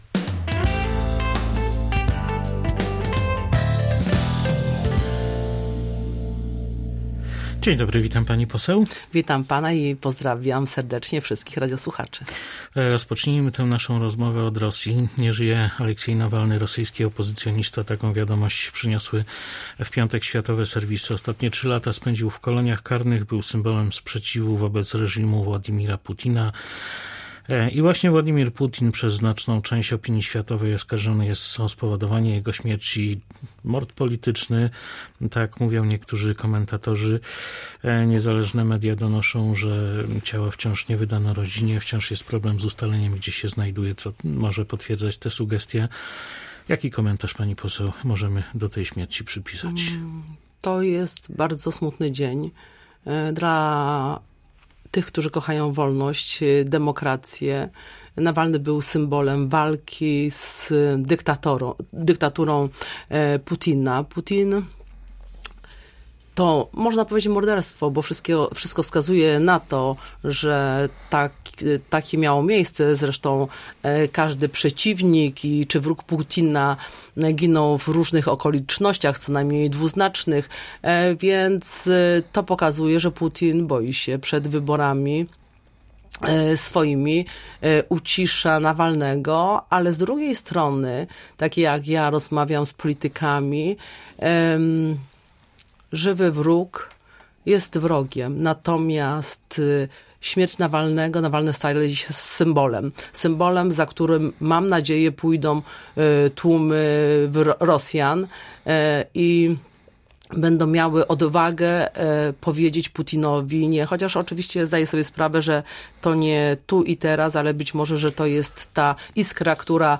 Zdaniem dzisiejszego gościa Radia Rzeszów, Nawalny stał się ikoną oporu w Rosji, a po śmierci może stać się również symbolem, który poruszy rosyjskie społeczeństwo.